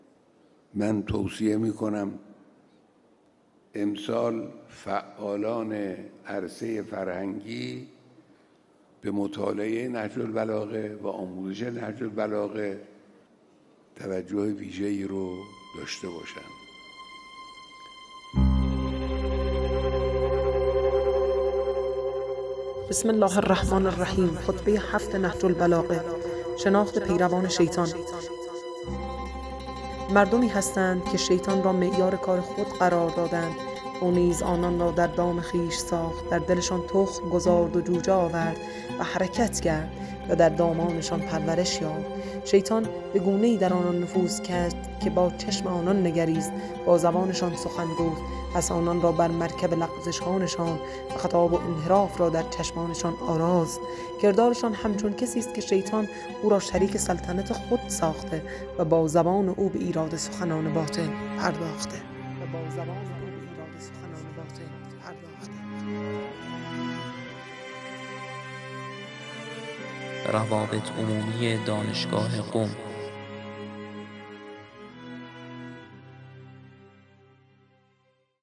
در جریان این پویش از دانشجویان، استادان و کارکنان خواسته شد که از هر نامه، کلام و خطبه‌های نهج‌البلاغه را که دوست دارند با صدا خود خوانده و ارسال کنند.